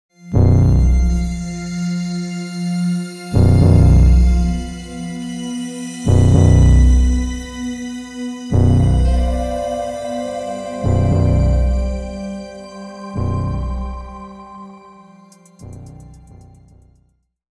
Download Creepy sound effect for free.
Creepy